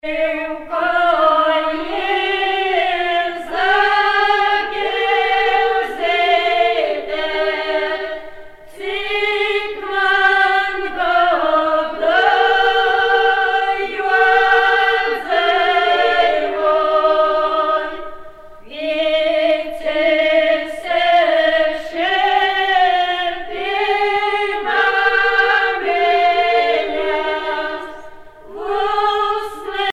Etnografiskais ansamblis
Pièce musicale éditée